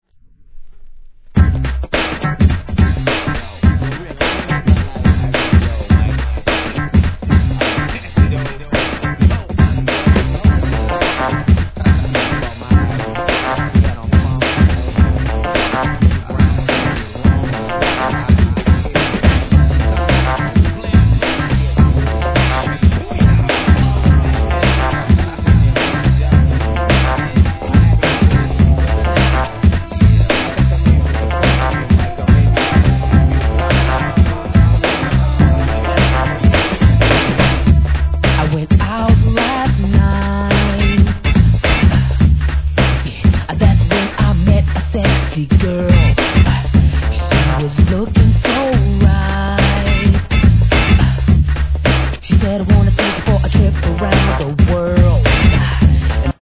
HIP HOP/R&B
NEW JACK SWING定番曲!!